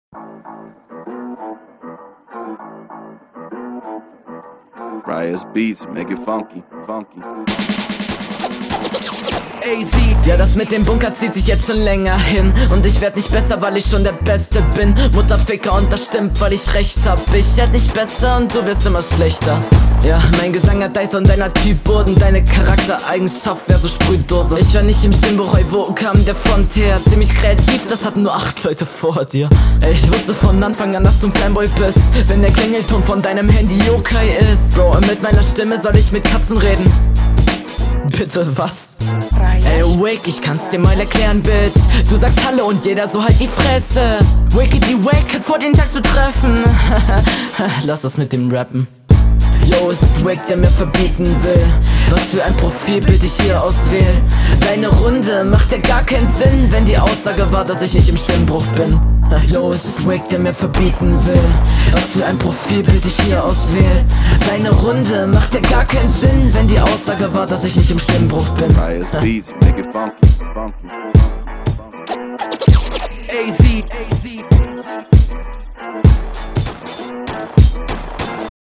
Flow: Seine Stimme und Betonung gefällt mir deutlich besser Text: Seine Konter finde ich nicht …